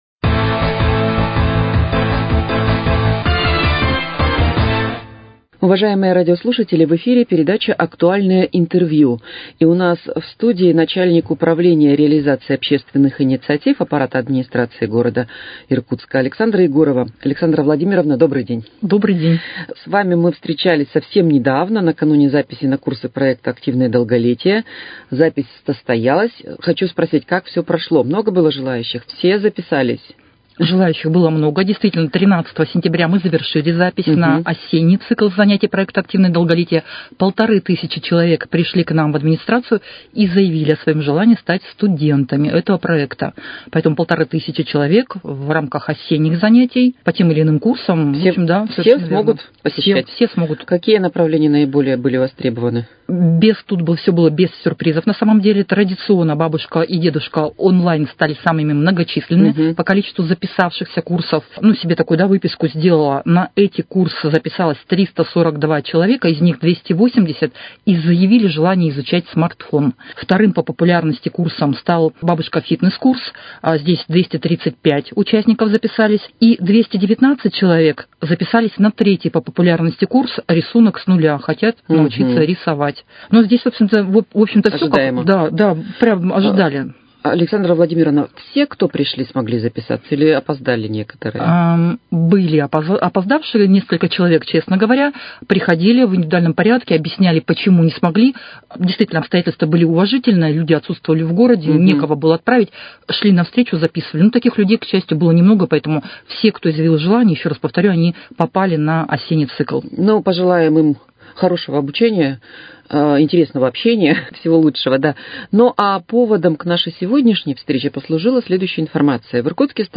Актуальное интервью: О старте конкурса «Миллион на добрые дела»